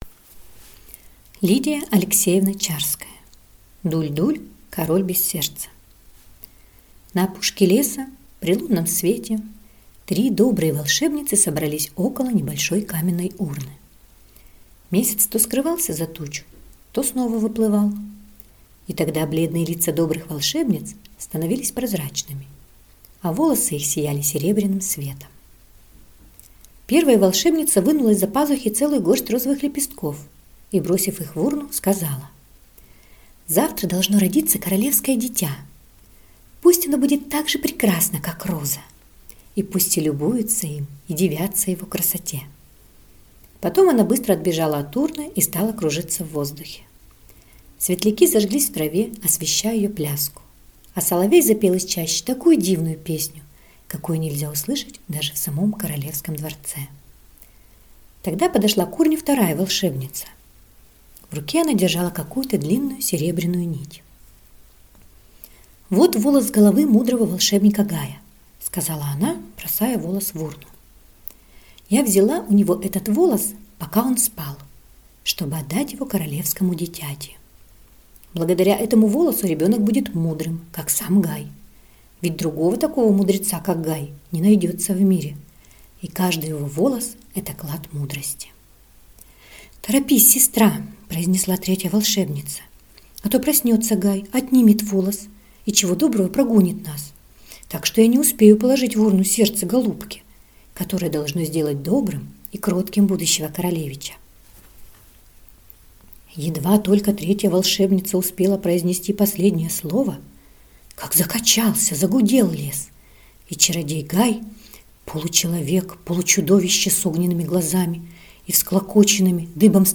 Аудиокнига Дуль-Дуль, король без сердца | Библиотека аудиокниг